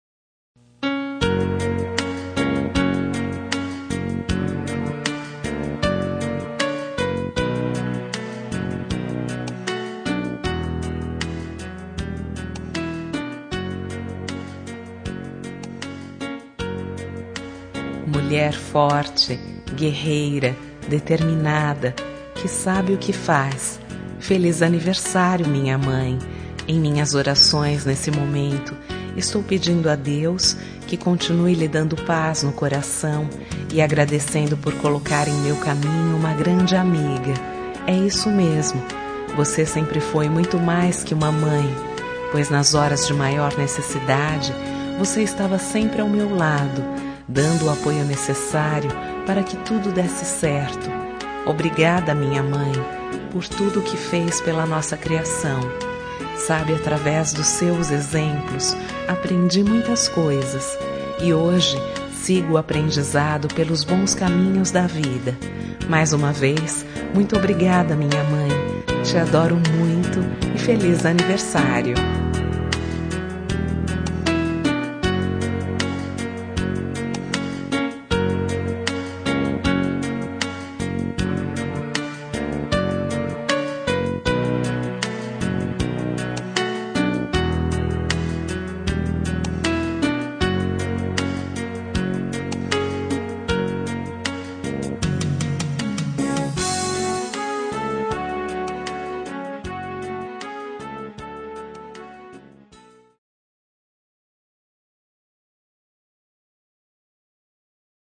Telemensagem Aniversário de Mãe – Voz Feminina – Cód: 1404